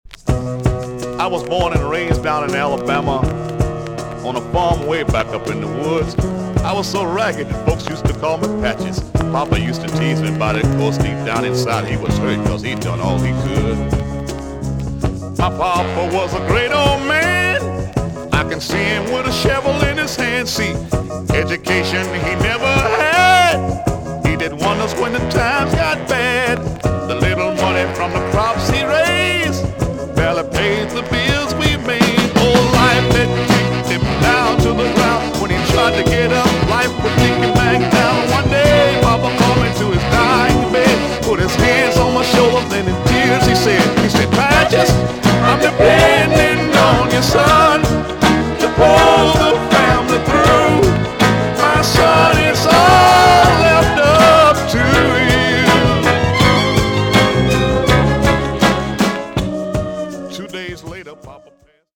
EX- 音はキレイです。
WICKED SOUL TUNE!!